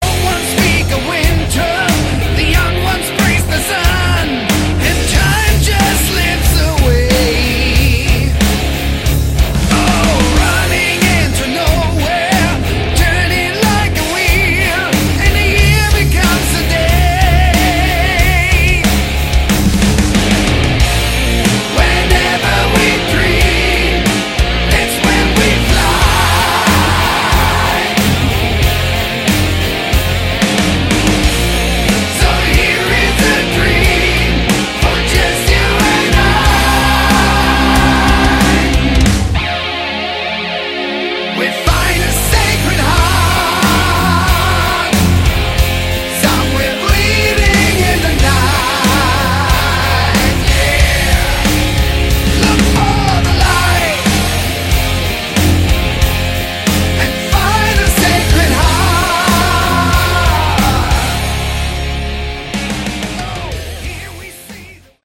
Category: Hard Rock
This time more of a power metal feel.